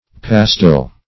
Pastil \Pas"til\, Pastille \Pas*tille"\, n. [F. pastille, L.